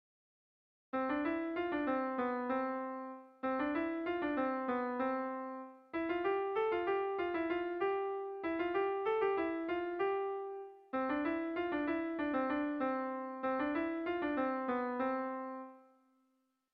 Haurrentzakoa
AAB